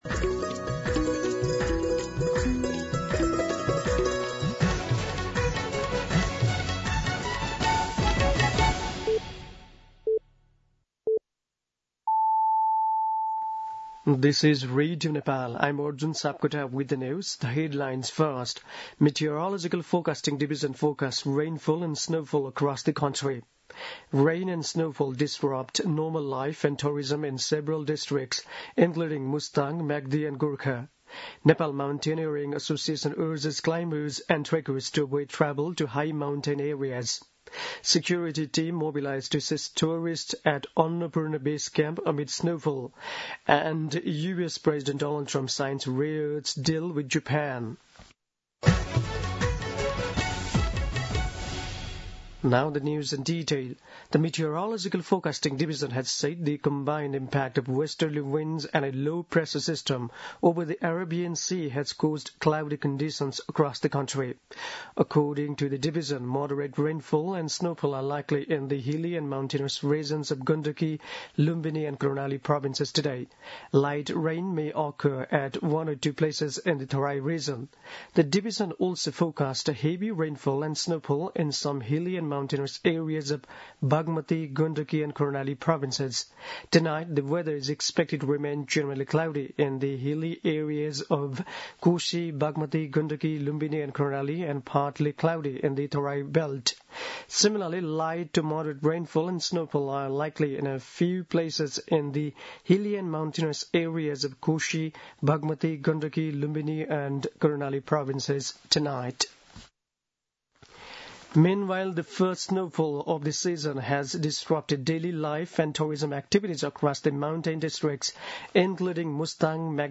दिउँसो २ बजेको अङ्ग्रेजी समाचार : ११ कार्तिक , २०८२
2-pm-English-News-12.mp3